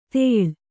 ♪ tēḷ